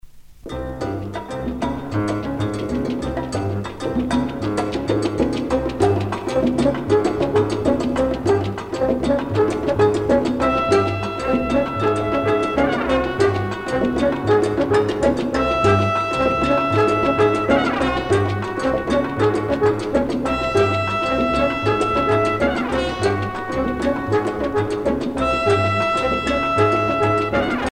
danse : mambo
Pièce musicale éditée